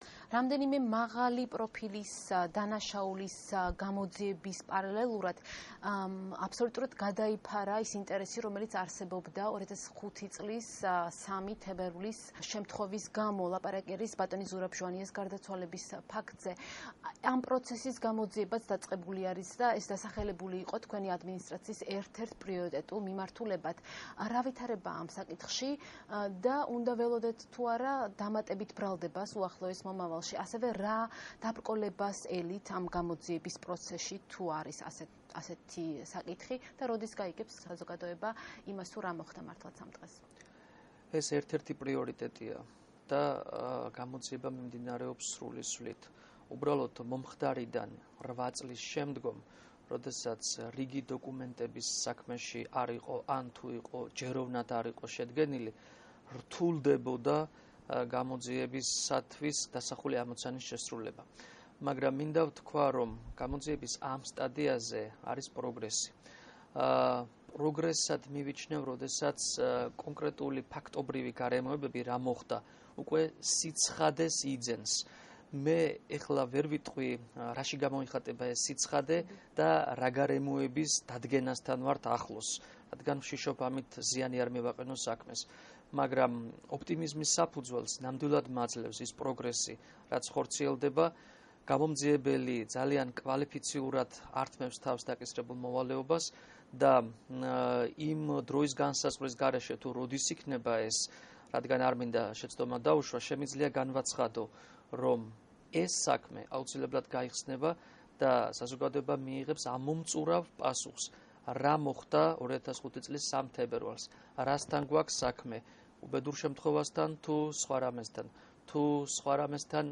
ინტერვიუ არჩილ კბილაშვილთან
საქართველო-ინტერვიუ